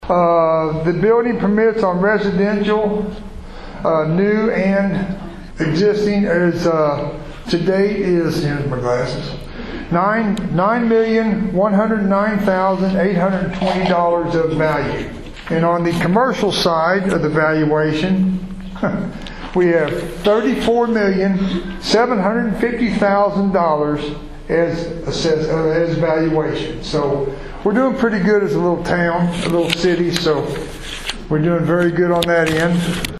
Farmington Mayor Speaks At Chamber Luncheon Event
Farmington, Mo. (KFMO) - Farmington Mayor Larry Forsythe spoke at the Farmington Chamber of Commerce monthly luncheon on Thursday.
farmingtonmayorspeaksatchamberluncheoneventforsythe.mp3